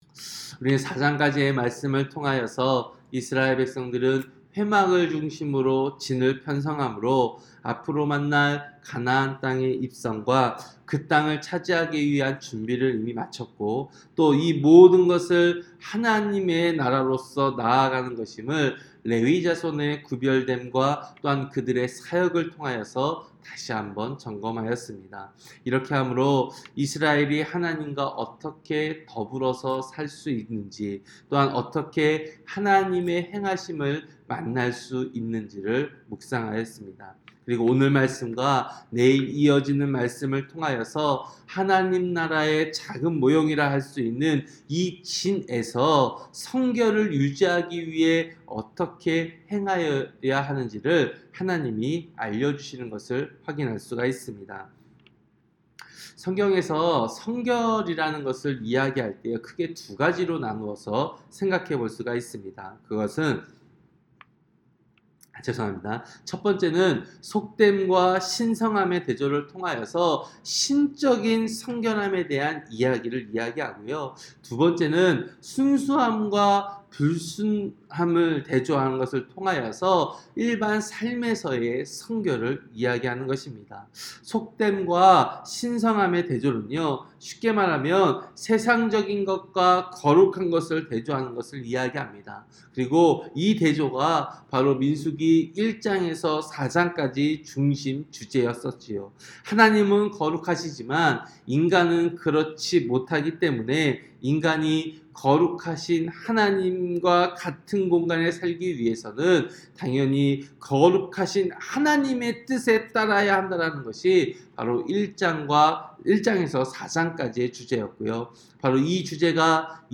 새벽설교-민수기 5장